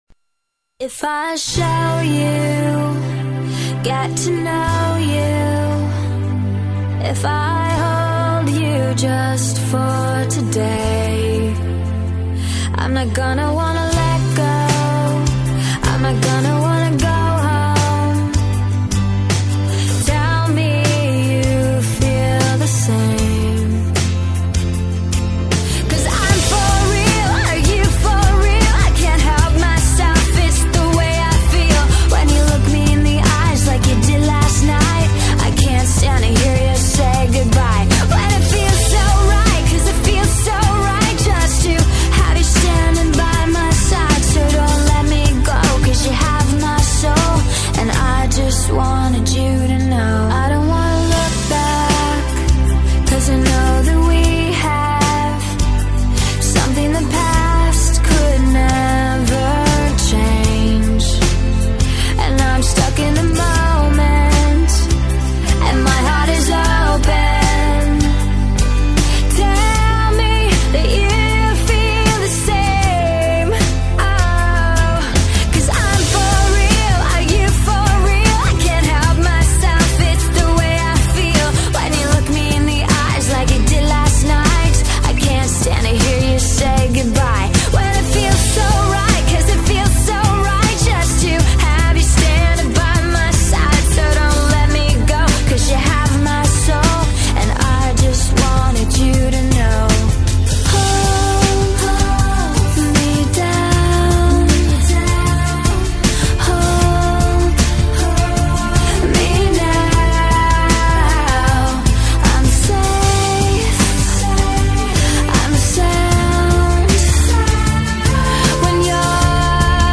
Категория: Рок, Альтернатива